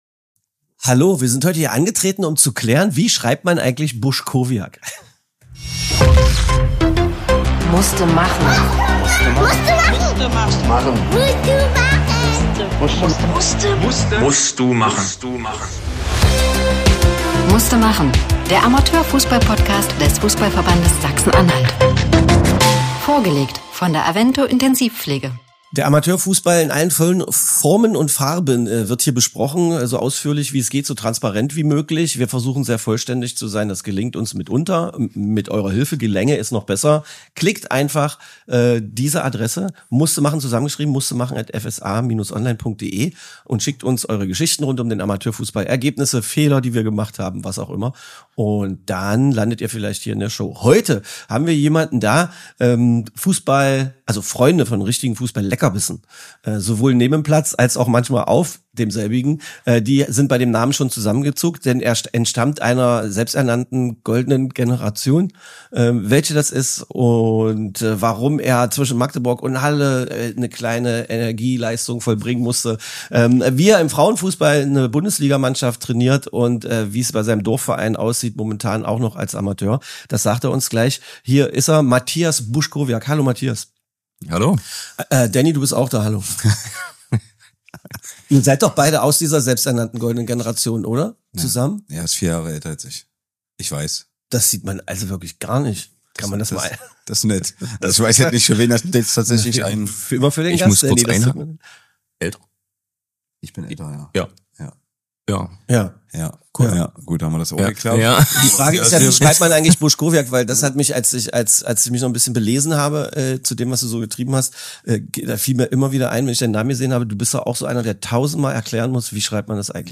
Ein Talk über Fußball in allen Facetten – ehrlich, nahbar, emotional.